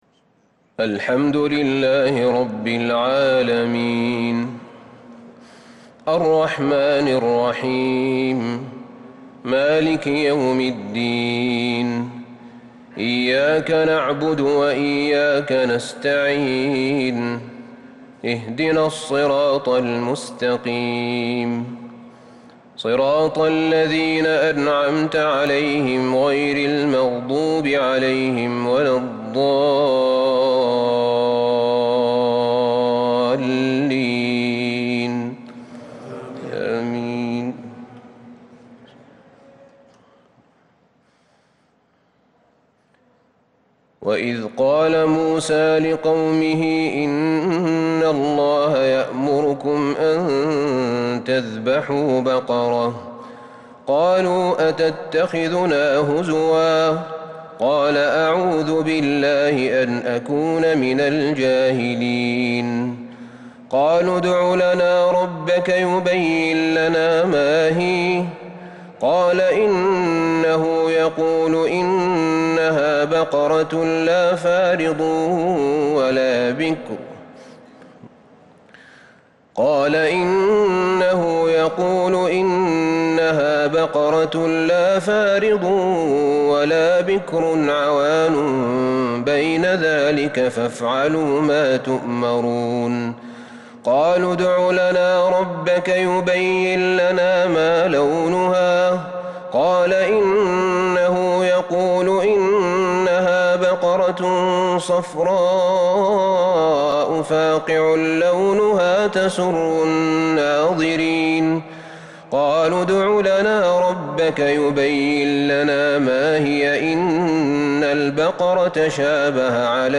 تراويح ليلة 30 رمضان 1442ھ من سورة البقرة (67-105) Taraweeh 30st night Ramadan 1442H > تراويح الحرم النبوي عام 1442 🕌 > التراويح - تلاوات الحرمين